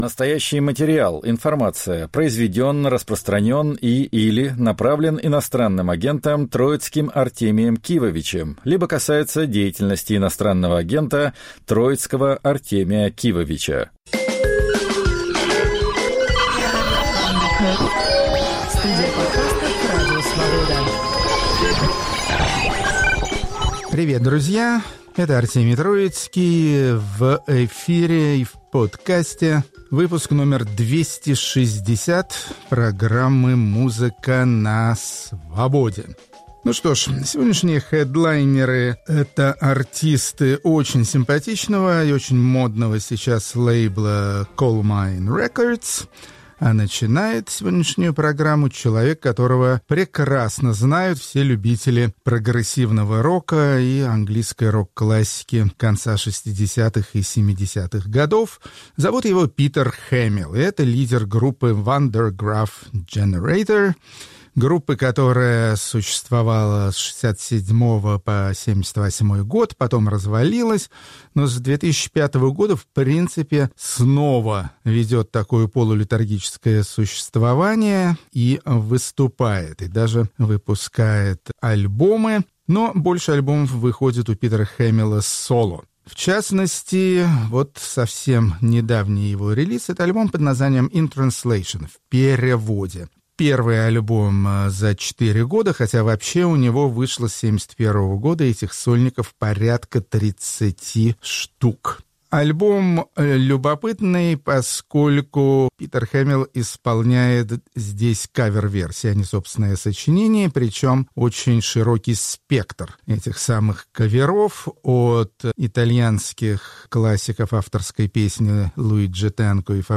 В новом эпизоде звучит орган Хаммонд - церковное изобретение 1930-х годов. Инструмент стал ключевым для развития соула и фанка в 1950-е, а сейчас переживет новую молодость.